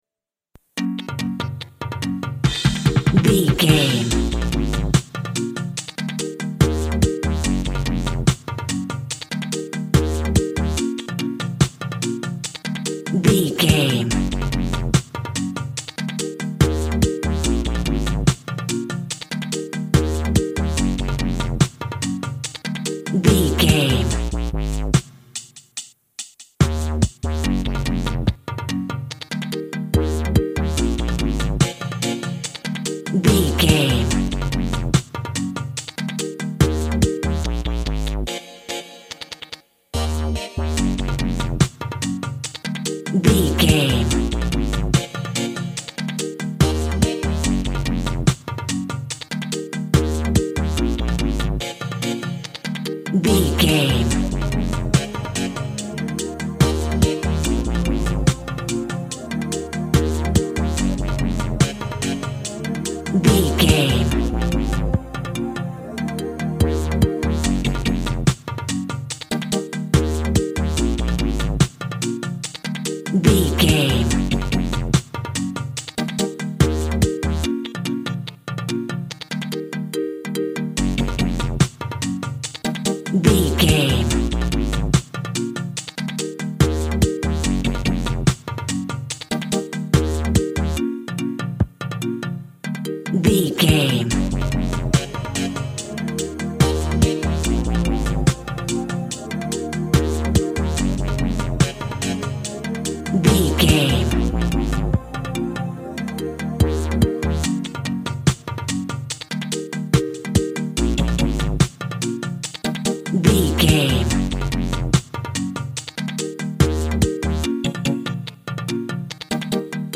Also with small elements of Dub and Rasta music.
Aeolian/Minor
B♭
drums
bass
guitar
piano
brass
pan pipes
steel drum